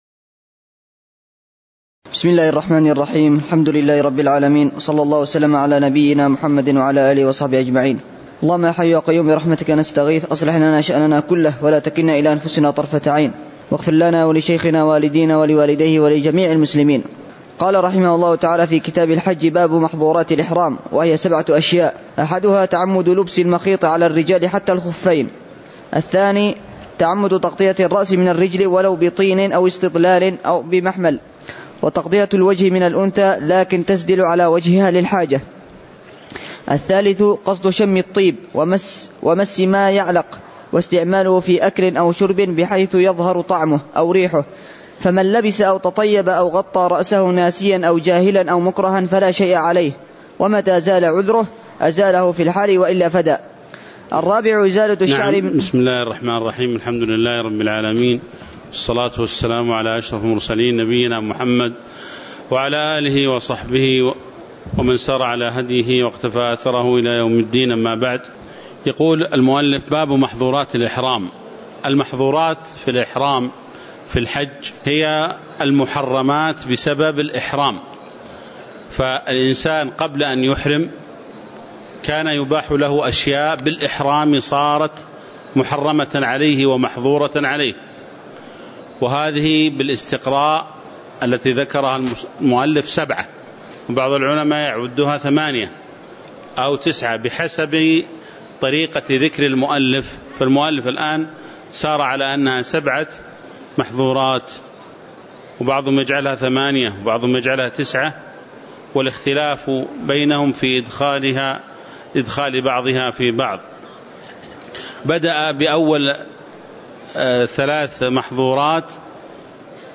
تاريخ النشر ٧ ذو القعدة ١٤٤٠ هـ المكان: المسجد النبوي الشيخ